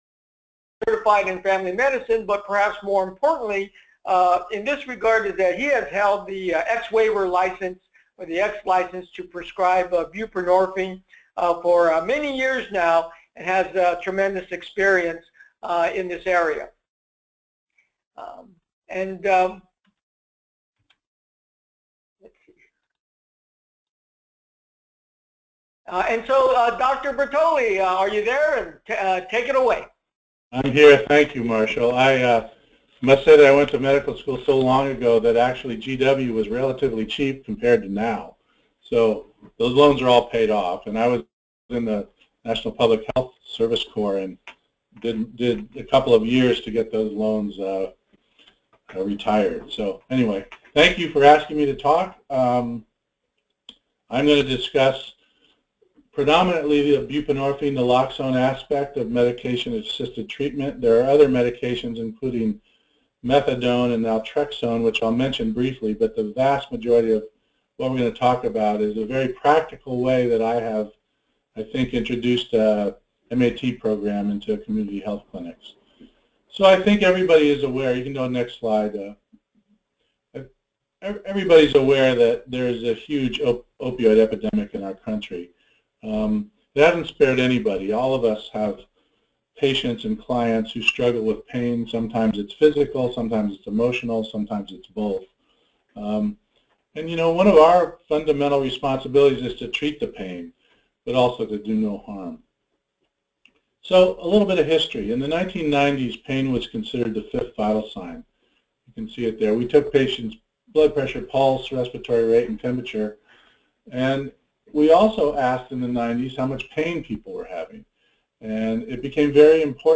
Webinar Recording